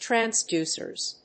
/træˈnsdusɝz(米国英語), træˈnsdu:sɜ:z(英国英語)/